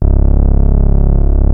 P.5 C#2 4.wav